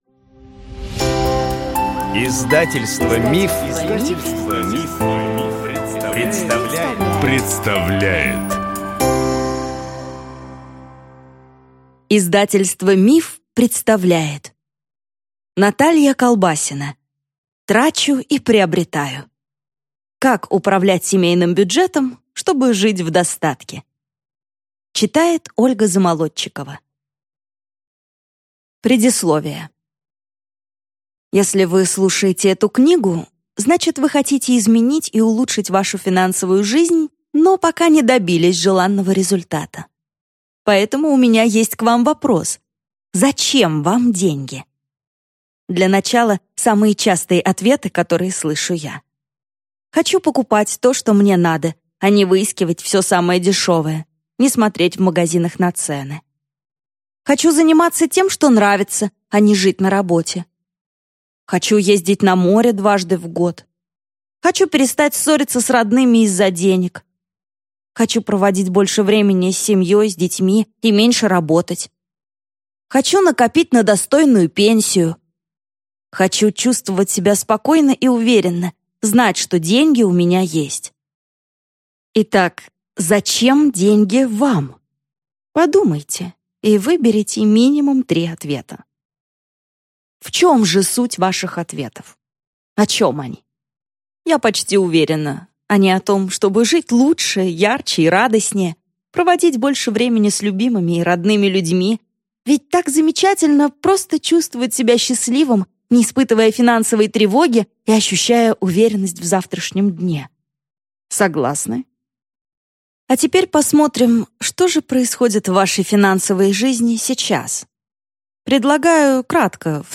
Аудиокнига Трачу и приобретаю. Как управлять семейным бюджетом, чтобы жить в достатке | Библиотека аудиокниг